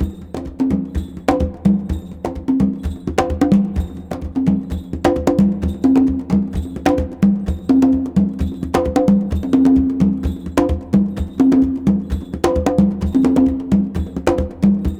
CONGABEAT3-R.wav